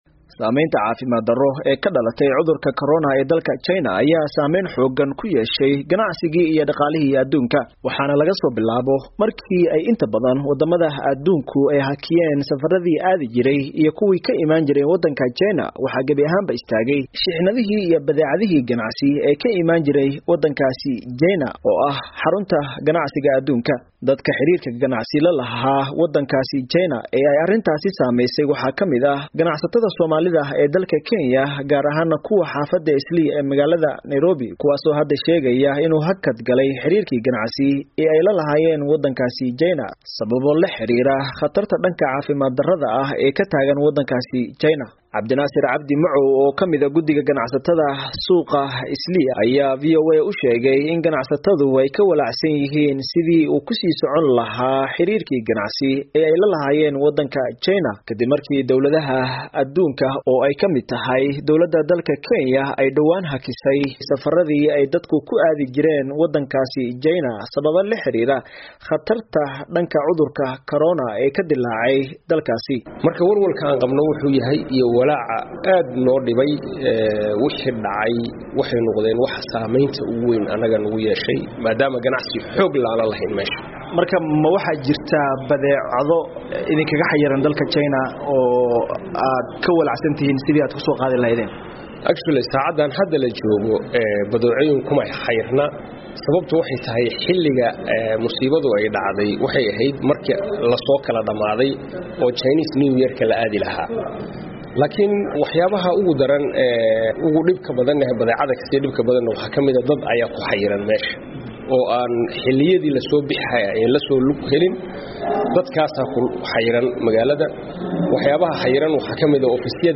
Warbixintan waxaa magaalada Nairobi ka soo diray wariyaha VOA ee